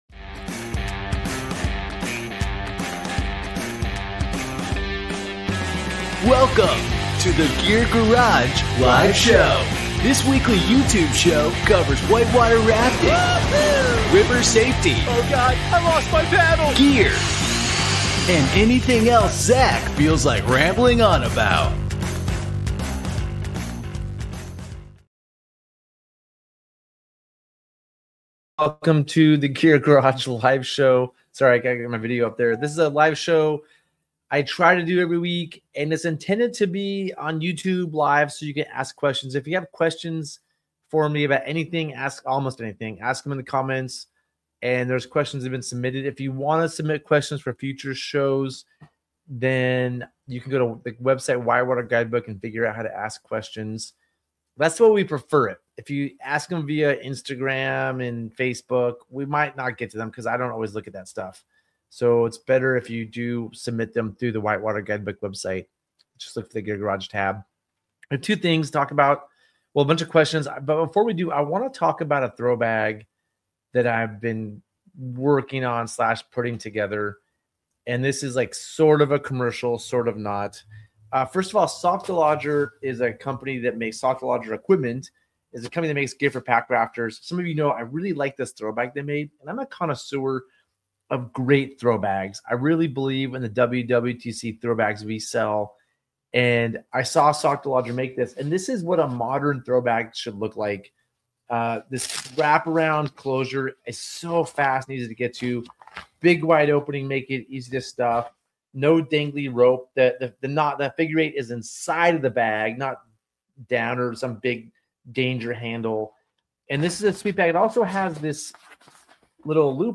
This podcast is the audio version of the Gear Garage Live Show, where we answer submitted questions and talk all things whitewater.